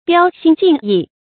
标新竞异 biāo xīn jìng yì
标新竞异发音
成语注音 ㄅㄧㄠ ㄒㄧㄣ ㄐㄧㄥˋ ㄧˋ